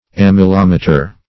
Search Result for " amylometer" : The Collaborative International Dictionary of English v.0.48: Amylometer \Am`y*lom"e*ter\, n. [Amylum + -meter.] Instrument for determining the amount of starch in a substance.